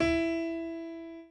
b_pianochord_v100l1o5e.ogg